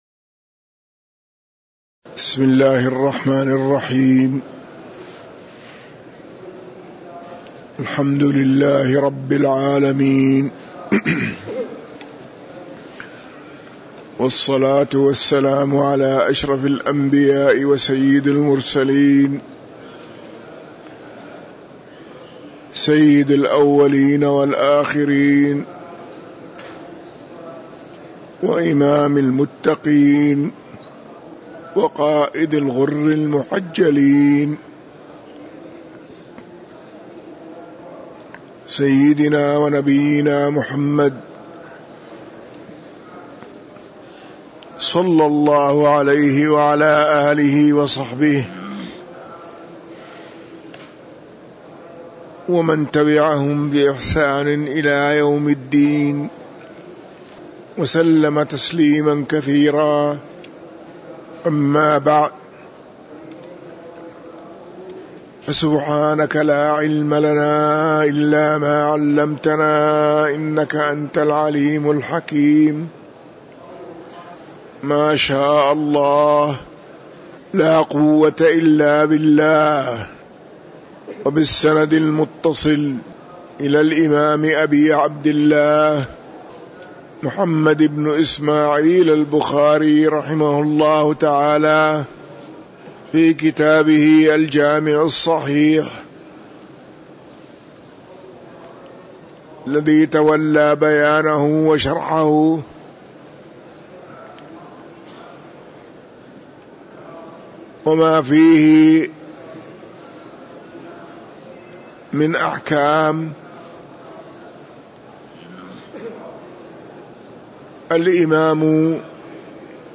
تاريخ النشر ٢٣ جمادى الأولى ١٤٤٣ هـ المكان: المسجد النبوي الشيخ